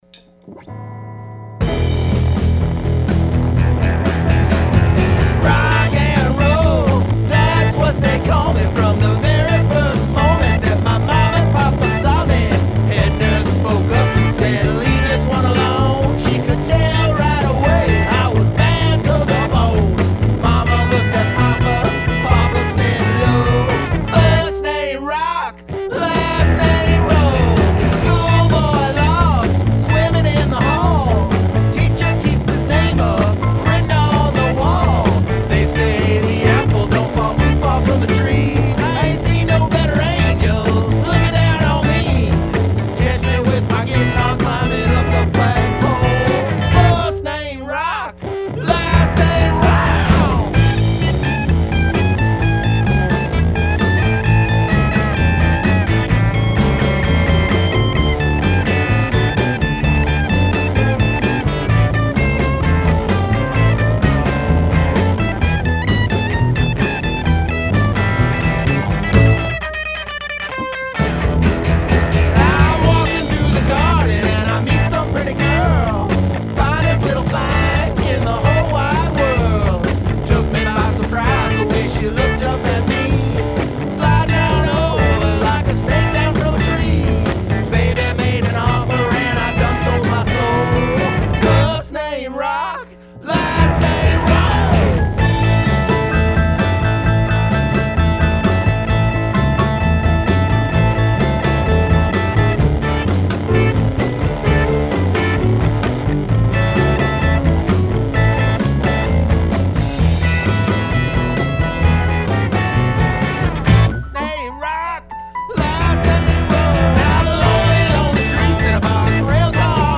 Studio rough bounces: